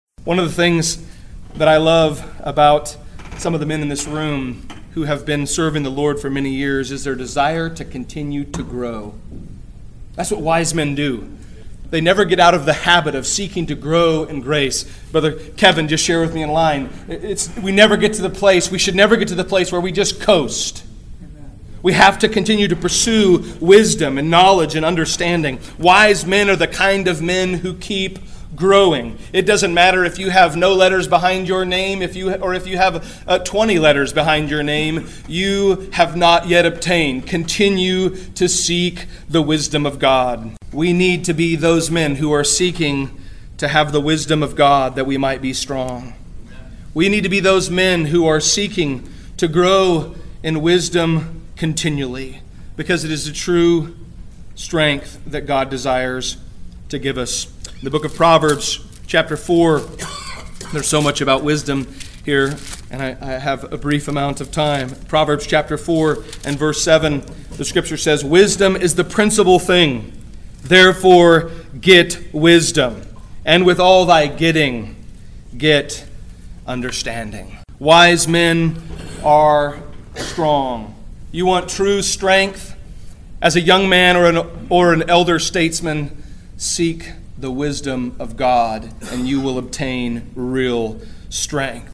Conference
Church Leaders Luncheon